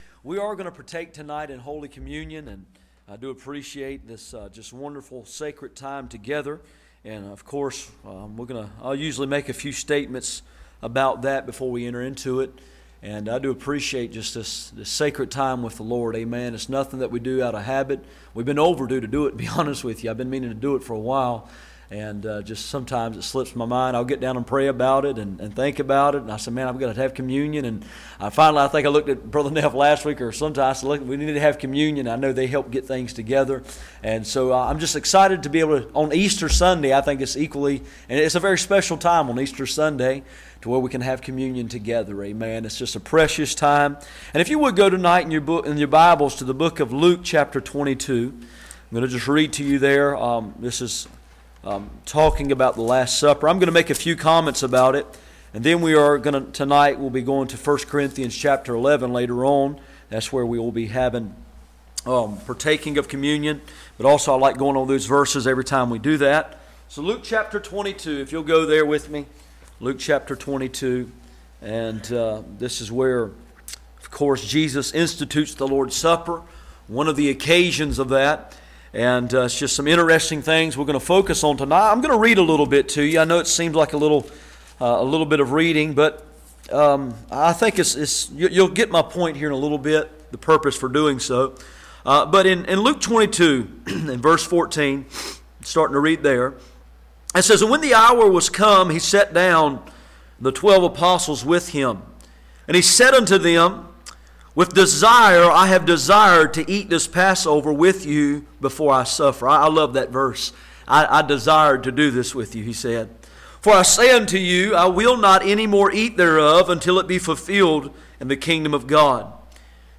None Passage: Luke 22:16-30, 1 Corinthians 11:17-34 Service Type: Sunday Evening